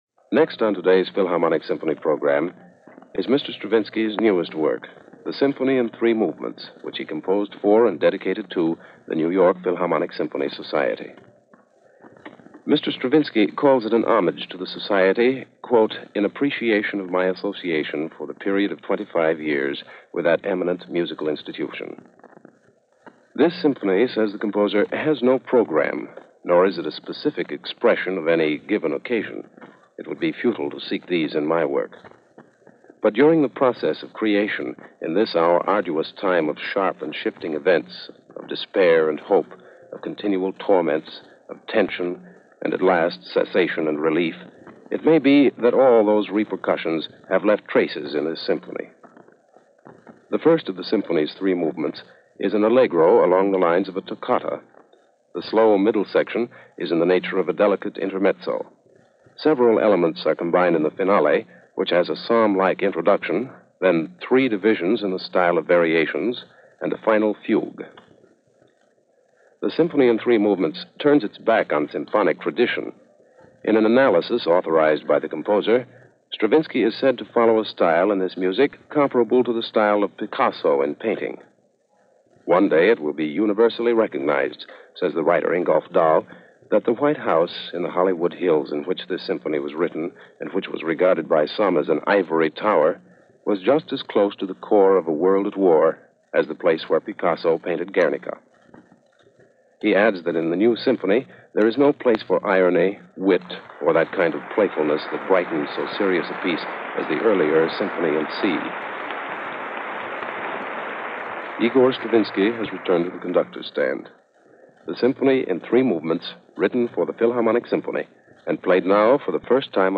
A Radio Premier this weekend.
The sound is a bit fuzzy in places, but it’s history . . ..